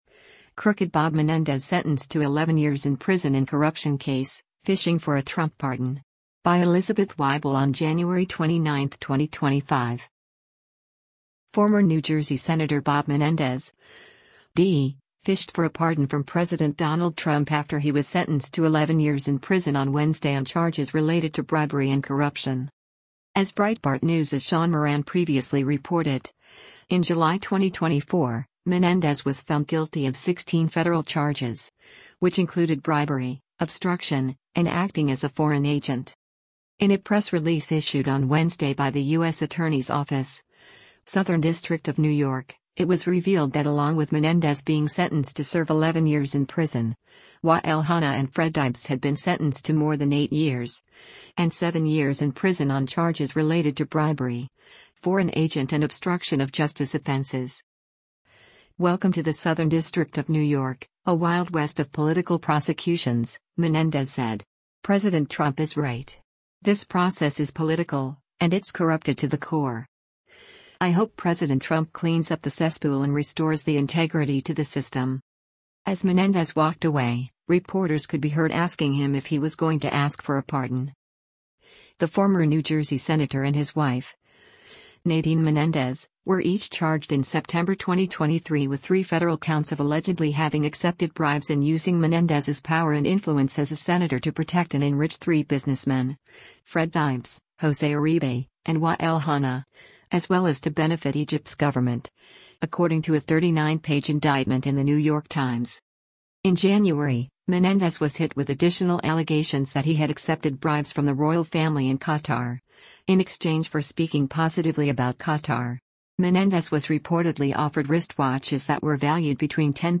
Former Sen. Bob Menendez (D-NJ) departs Manhattan Federal Court after his sentencing on Ja
As Menendez walked away, reporters could be heard asking him if he was “going to ask for a pardon.”